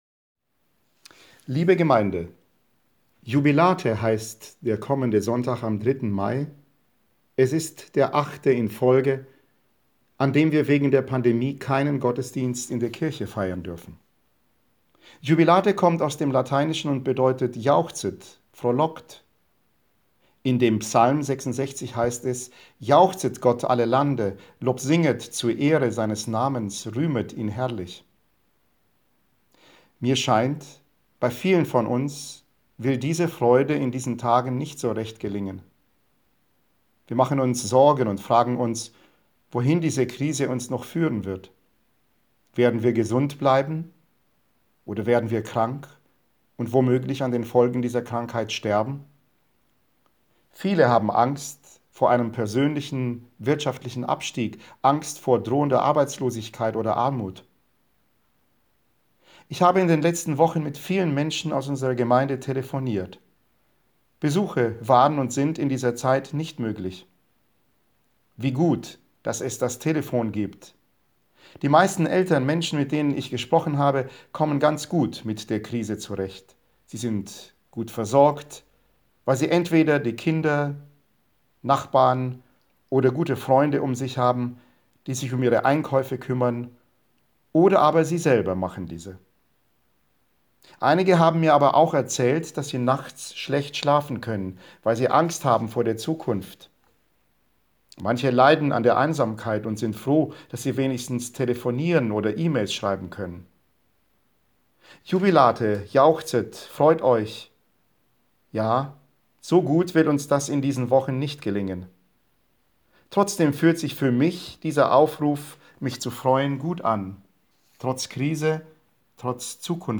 Predigt am Sonntag Jubilate - 3.5.2020